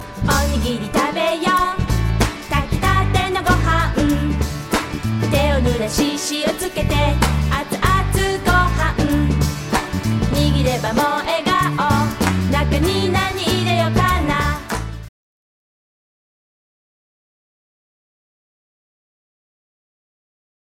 北海道の帯広に当時できたばかりのスタジオでレコーディング